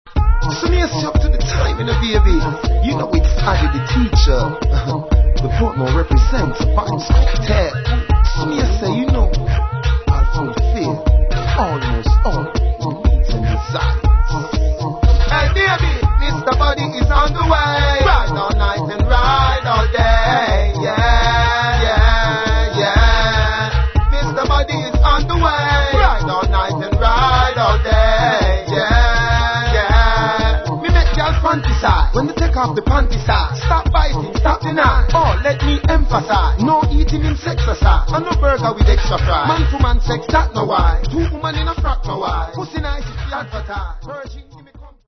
TOP 10 DANCEHALL